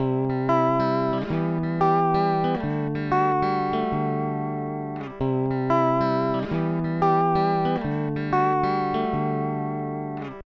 2. Vibrato
El vibrato es un efecto que modula la afinación de la guitarra, haciéndola subir y bajar todo el tiempo.
En combinación con la señal en seco, hace que el resultado se vea como que la guitarra “vibre” mientras tocas.
guitarra-con-vibrato_h4plei.ogg